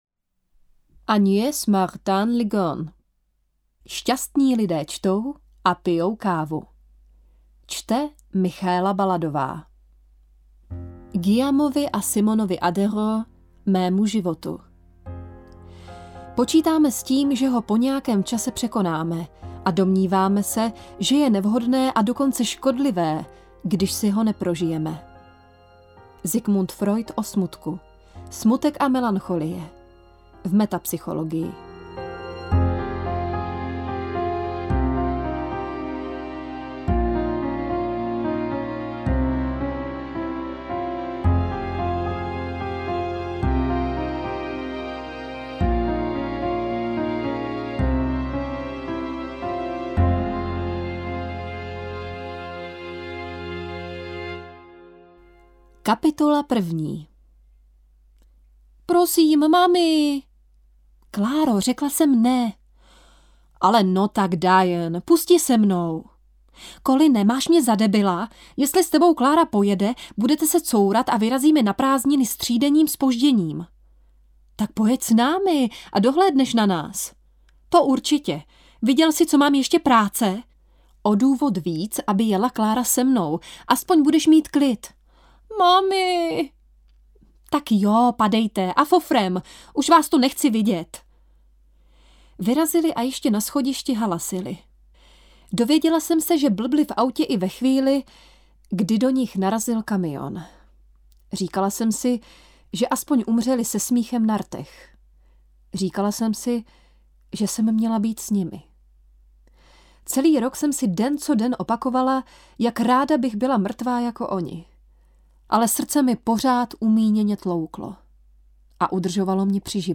Interpret:  Michaela Baladová
AudioKniha ke stažení, 10 x mp3, délka 4 hod. 46 min., velikost 653,2 MB, česky